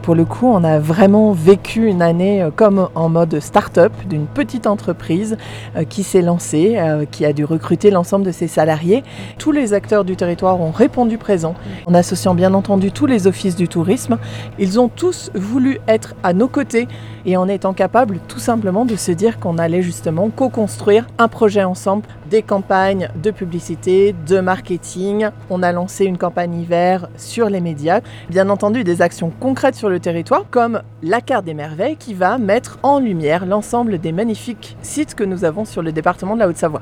Après un an d’existence de l’organisme Patricia Mahut conseillère départementale membre du comité fait le point sur ce qui a été réalisé lors de cette première année.